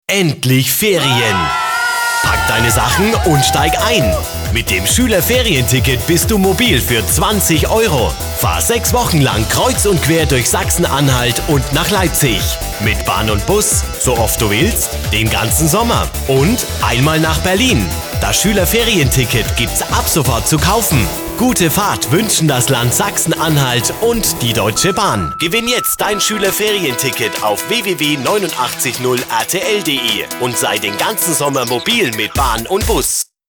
Werbestimme, Comedystimme, Radiowerbung, Fernsehwerbung, Radiomoderator uvm.
fränkisch
Sprechprobe: Industrie (Muttersprache):